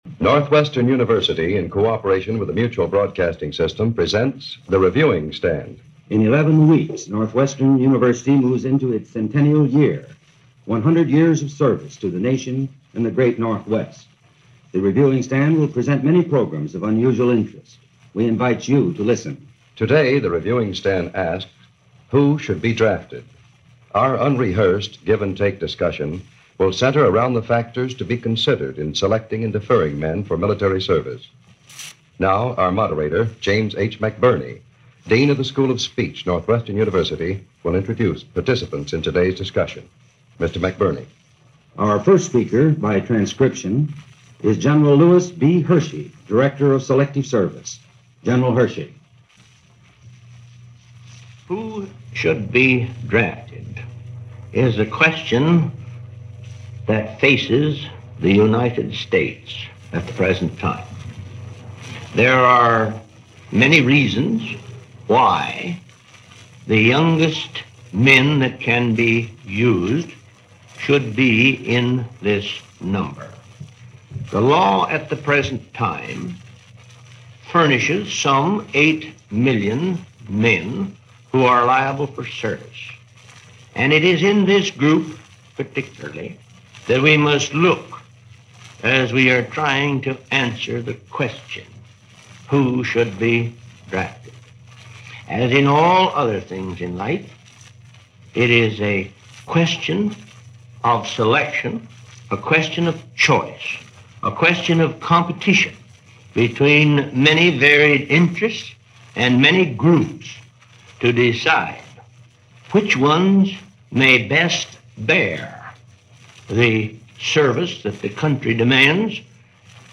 A discussion; Who Should Be Drafted? Broadcast on November 12, 1950 as part of the Northwestern Reviewing Stand series for Mutual.